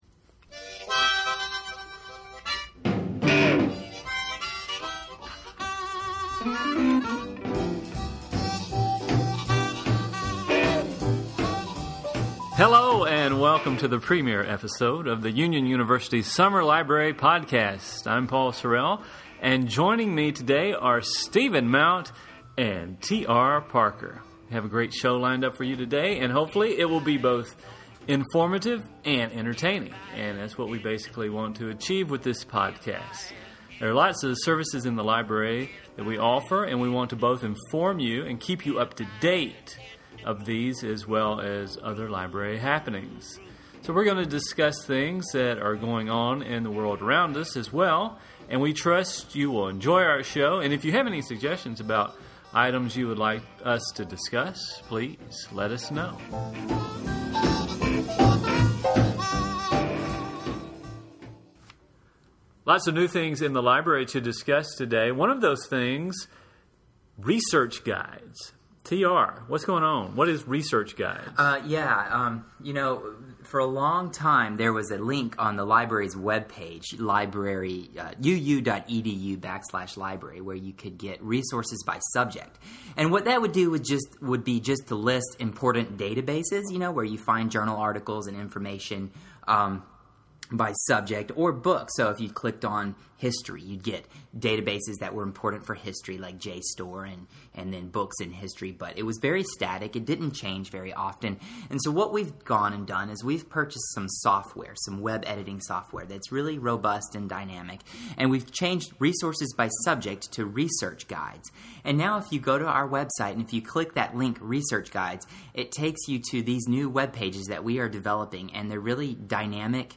In this episode of The Summar Library Podcast we discuss new dynamic Research Guides, Refworks, The Naxos Music Library, Spring Library Hours, The Movie Display, The Academy Awards and The Super Bowl. We laugh a little while we talk about what is going in the Library and in the world.